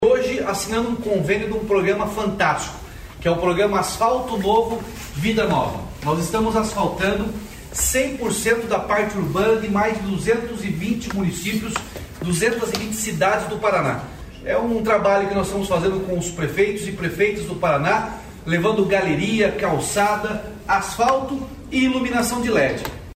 Sonora do governador Ratinho Junior sobre a liberação de R$ 66,7 milhões para 14 municípios no Asfalto Novo, Vida Nova